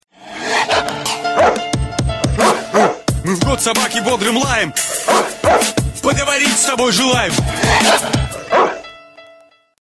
Приколы
Мелодия